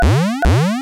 Category 🎮 Gaming
design effect game games mario sound videogames vintage sound effect free sound royalty free Gaming